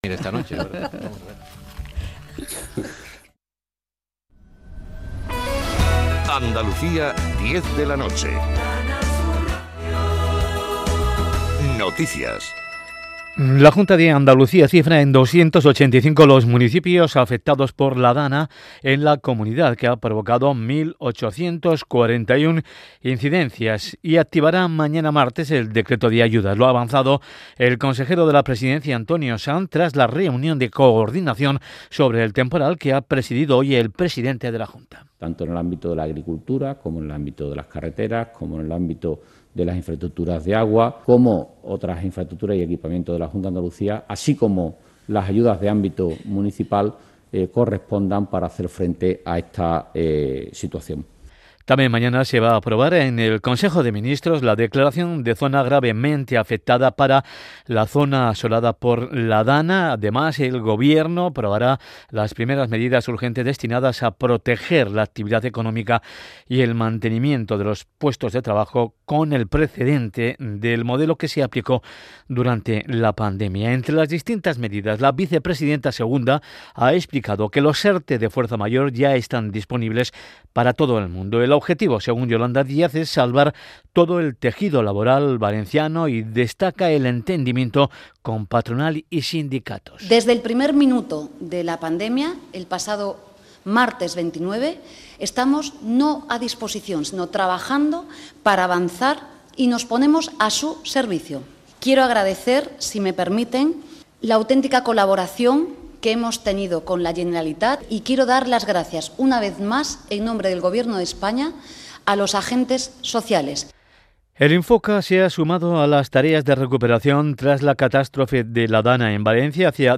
Canal Sur Radio y Radio Andalucía Información son las únicas cadenas que transmiten para toda Andalucía y el mundo el Concurso de Agrupaciones del Carnaval de Cádiz de manera íntegra.